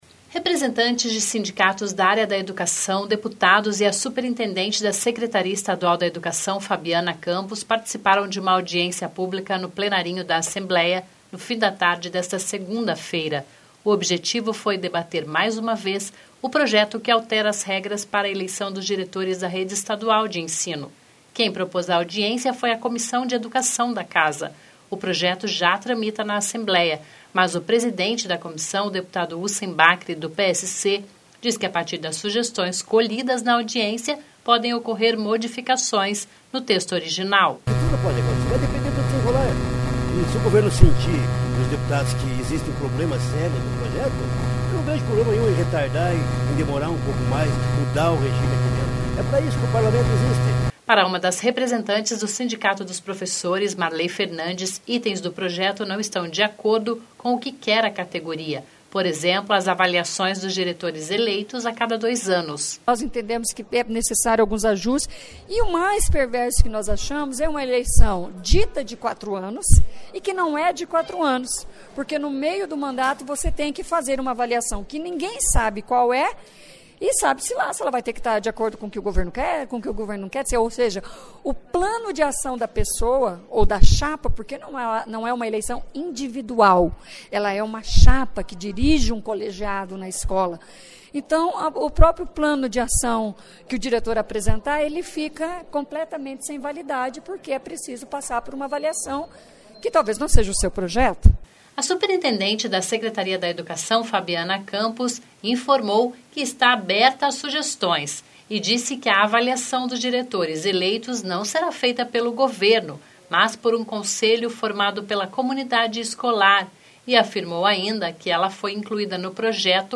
Audiência pública discute projeto de eleição dos diretores da Rede Estadual de Ensino